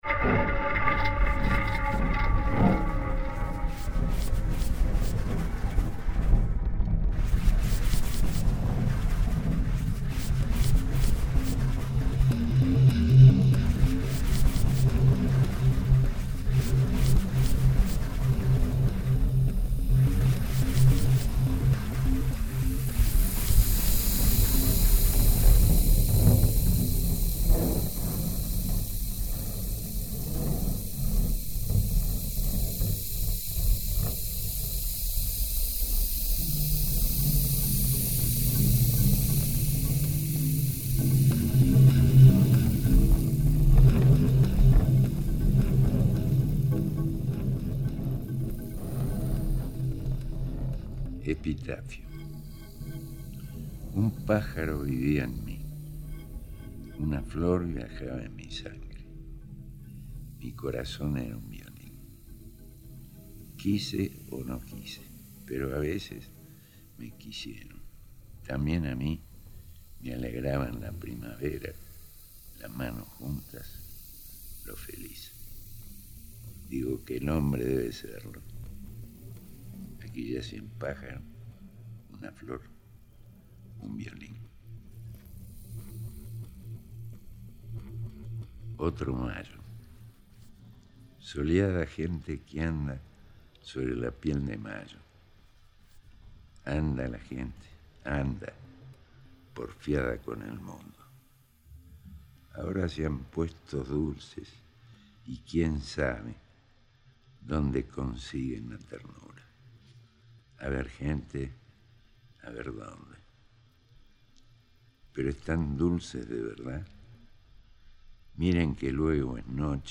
Instalación Sonora Multicanal
violonchelo